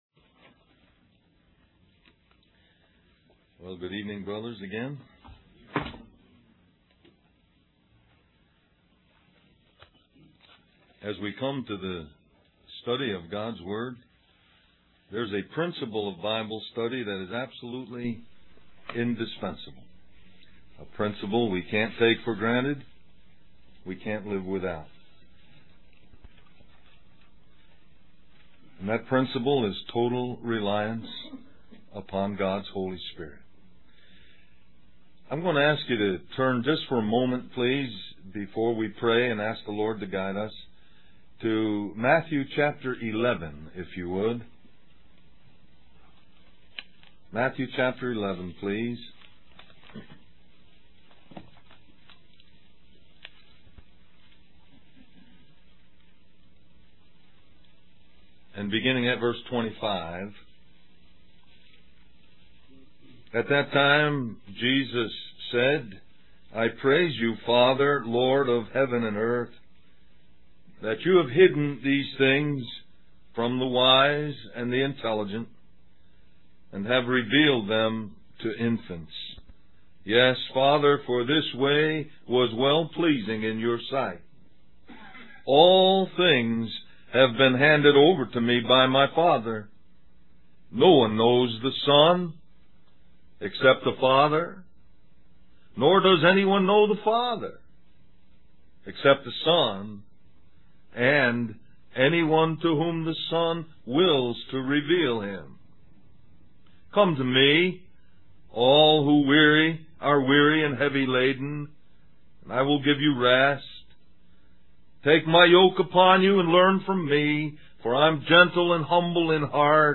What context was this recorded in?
Del-Mar-Va Men's Retreat